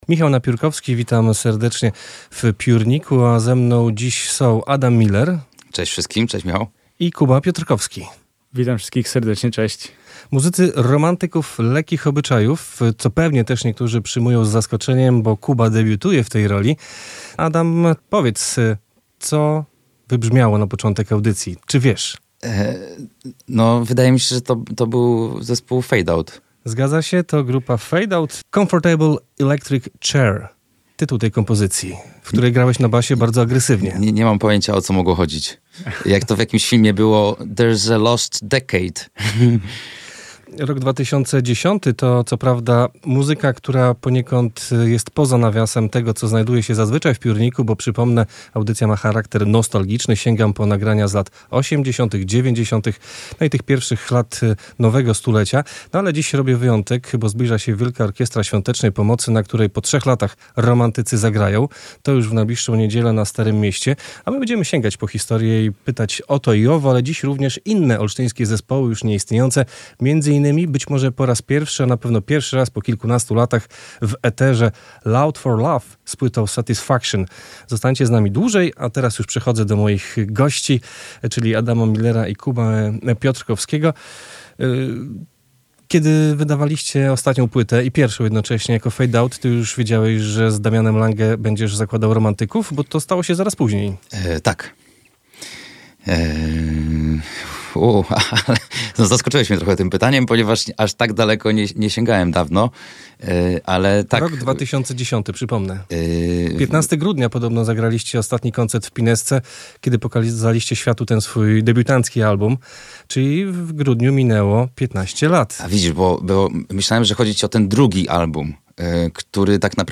Z kolei w wakacje zagrają w kilku największych polskich miastach na festiwalu Rockowizna. O powrocie i planach opowiedzieli na naszej antenie